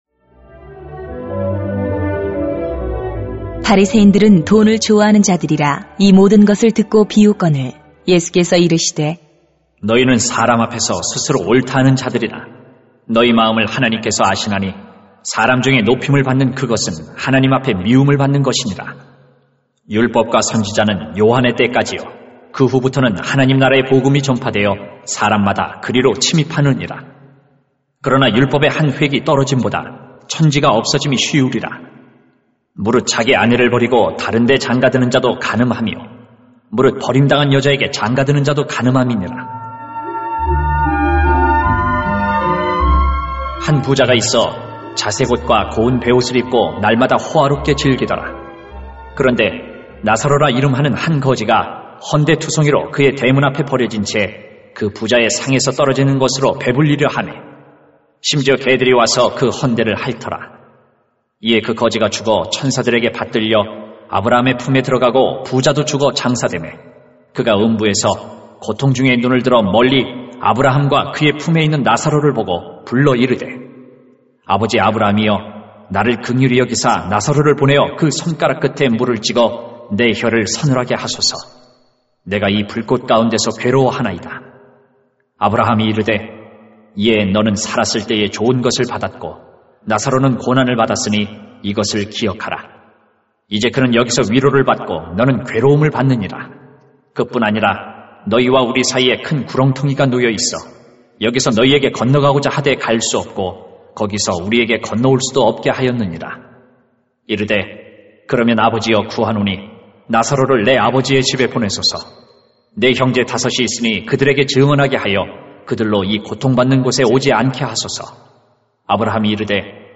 [눅 16:14-31] 이웃을 버리면 자신이 버림받습니다 > 새벽기도회 | 전주제자교회